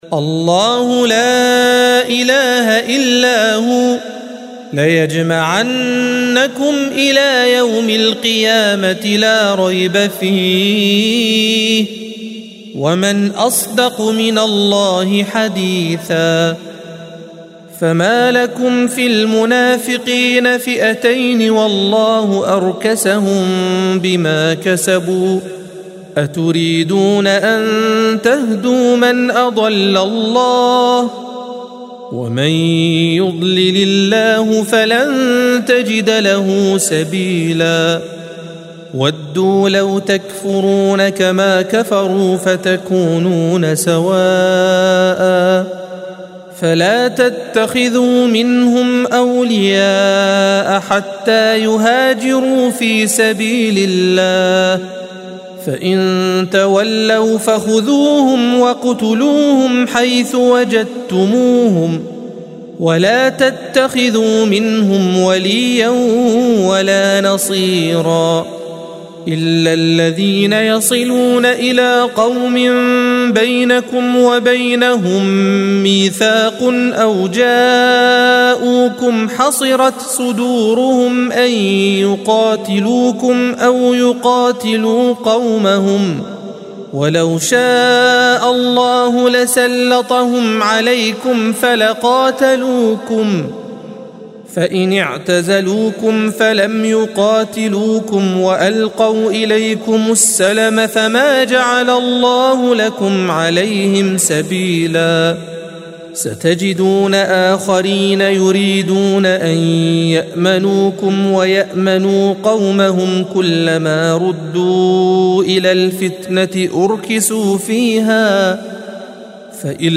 الصفحة 92 - القارئ